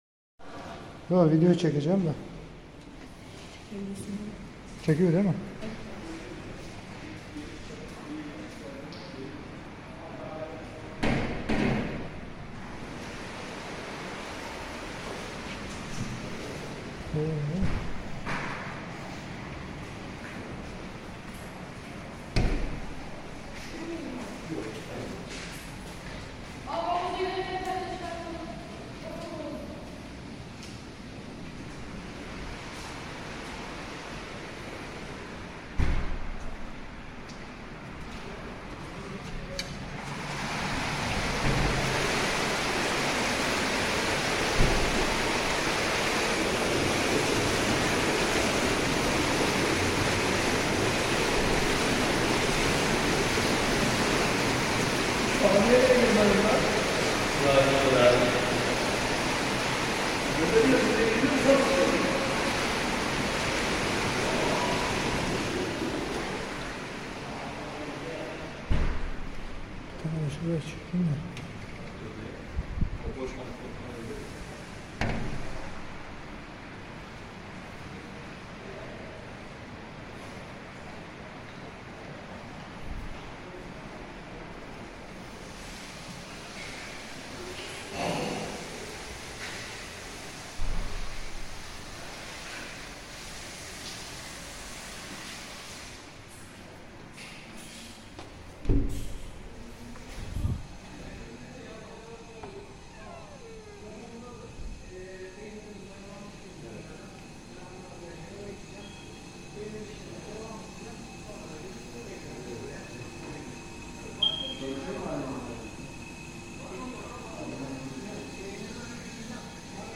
Akdeniz hamaml havuz saun buhar odası jakuzi